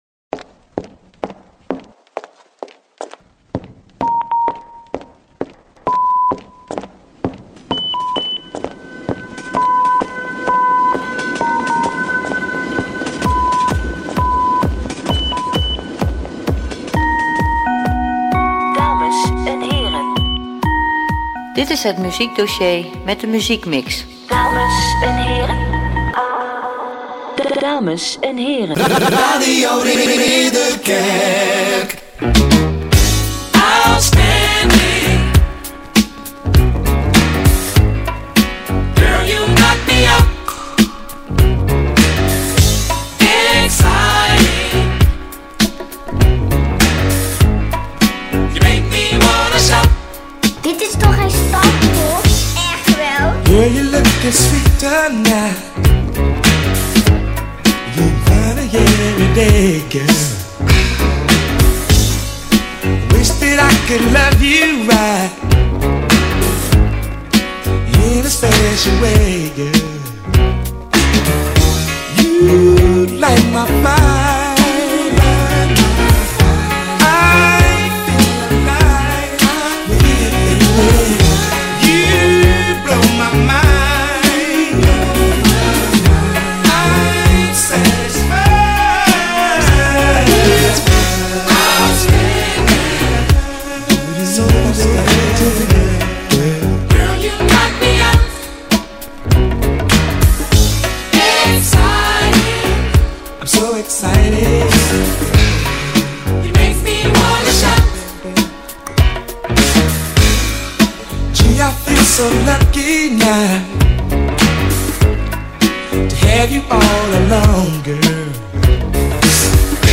In het programma hoor je Disco, Dance, soul, funk en R&B.